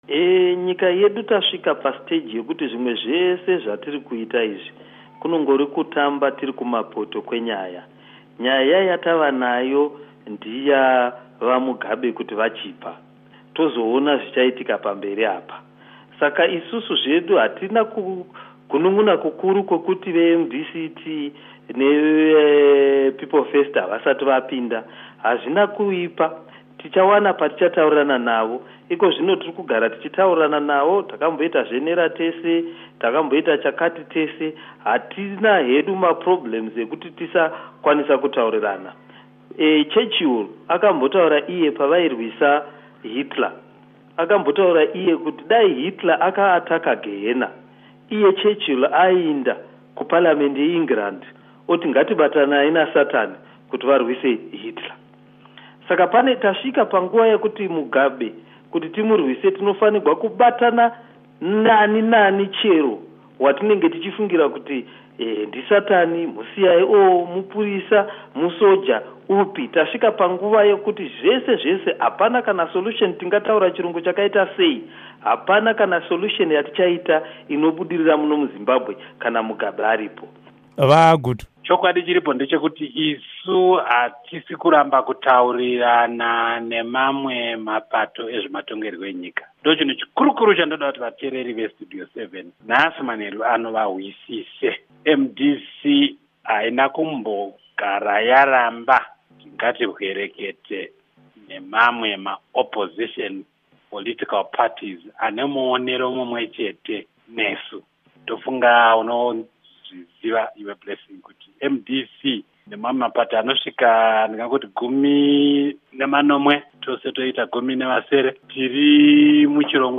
Hurukuro naVaObert Gutu naVaJacob Mafume